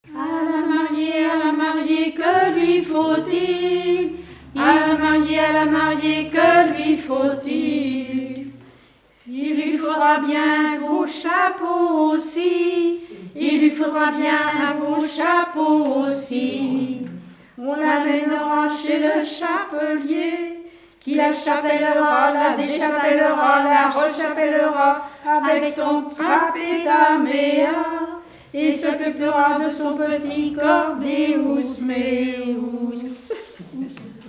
en trio
circonstance : fiançaille, noce
Genre énumérative
Pièce musicale inédite